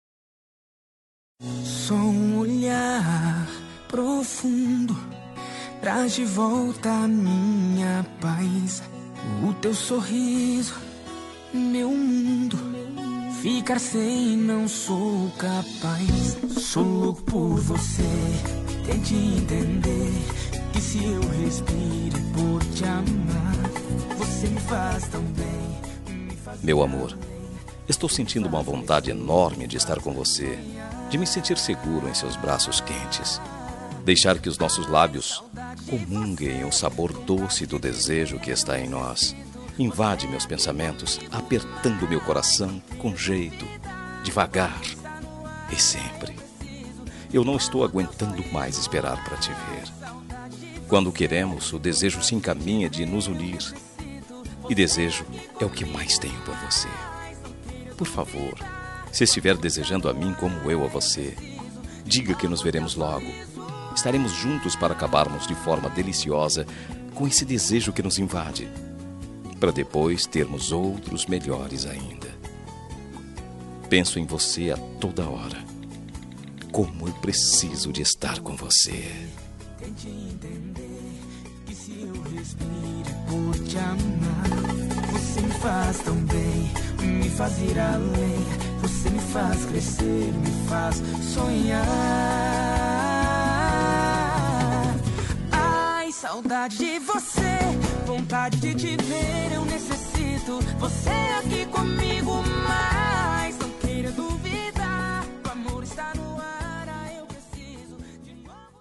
Telemensagem Romântica Distante – Voz Masculina – Cód: 8993